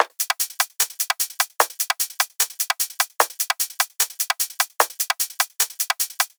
VR_top_loop_fromfoley_150.wav